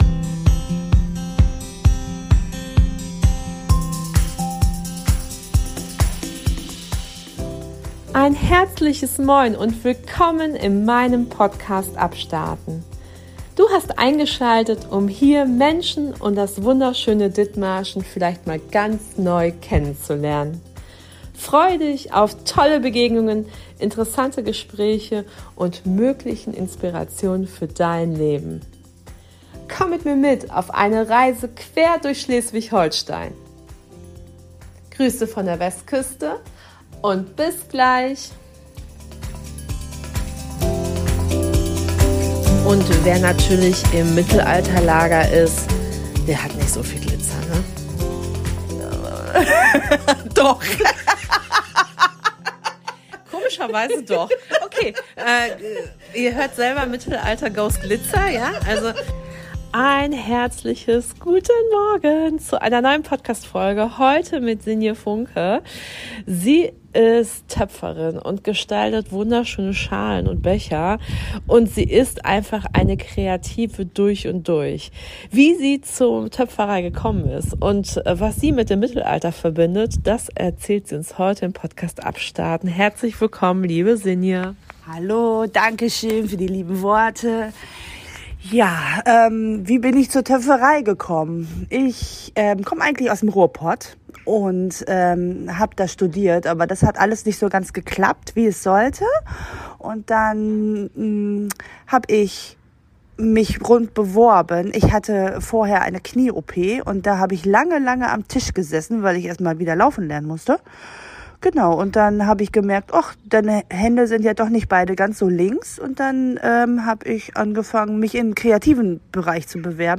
Seit gespannt auf ein spannendes Interview und erfahrt, was es mit dem Glitzer im Mittelalter auf sich hat.